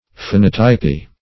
Search Result for " phonotypy" : The Collaborative International Dictionary of English v.0.48: Phonotypy \Pho*not"y*py\, n. A method of phonetic printing of the English language, as devised by Mr. Pitman, in which nearly all the ordinary letters and many new forms are employed in order to indicate each elementary sound by a separate character.